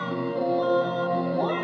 TGOD See It I Want It (Melody).wav